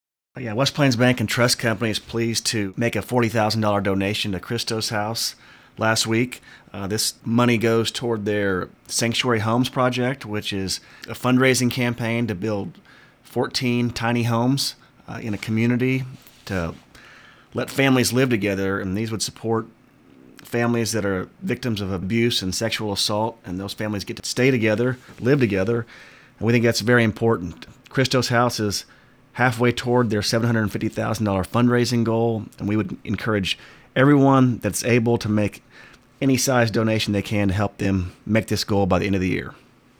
stepped into the studio to talk about this donation, and what it means.